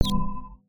UIClick_Soft Dreamy Whistle Wobble 04.wav